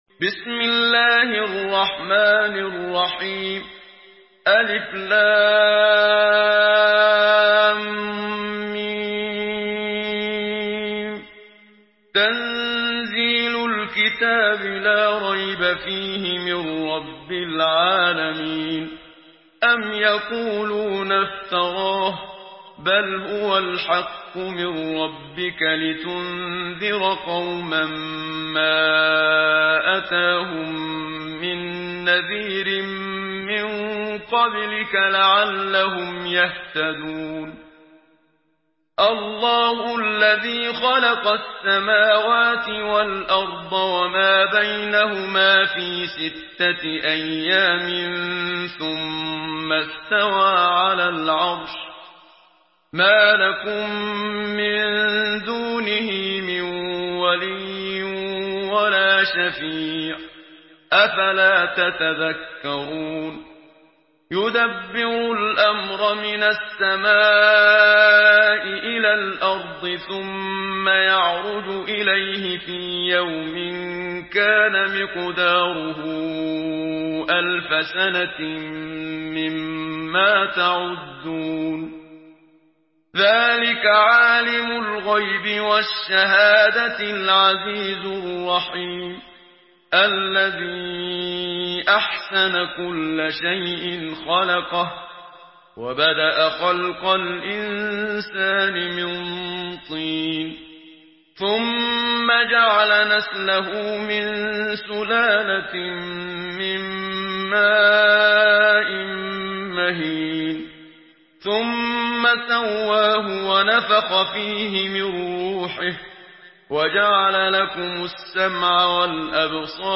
Surah আস-সাজদা MP3 by Muhammad Siddiq Minshawi in Hafs An Asim narration.
Murattal